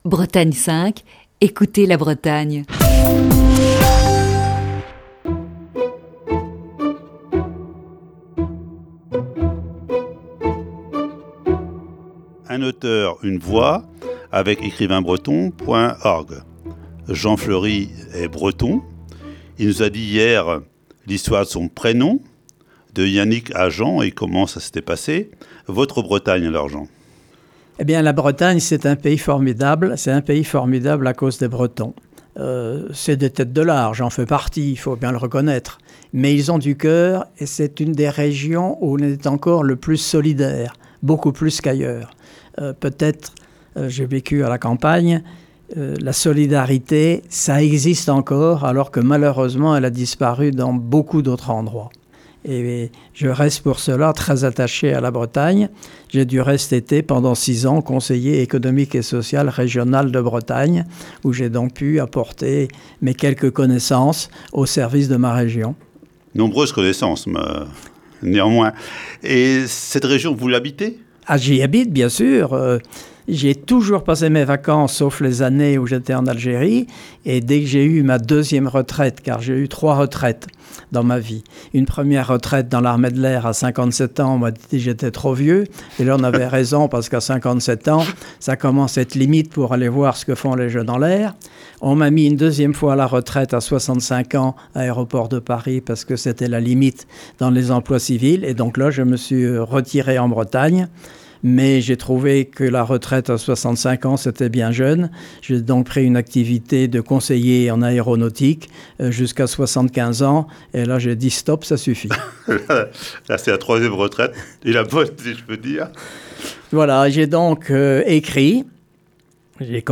Ce matin, deuxième partie de cette série d'entretiens.